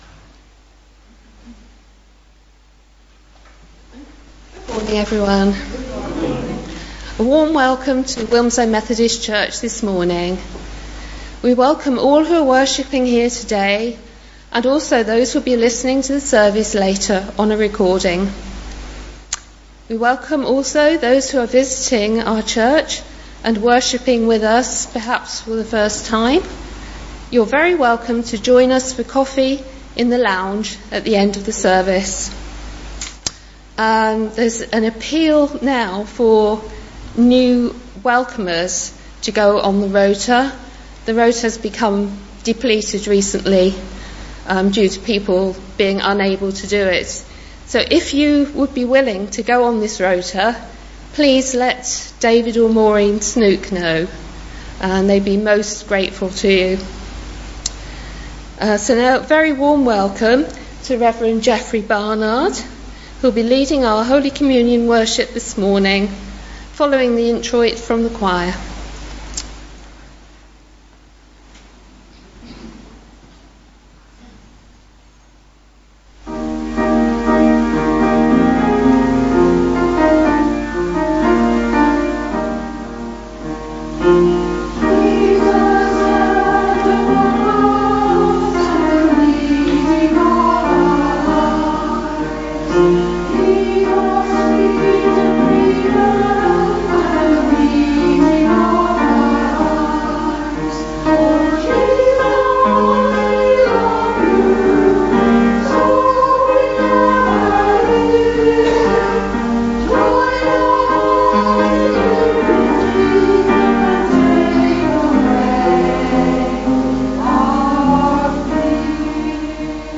2019-08-18 Communion
Genre: Speech.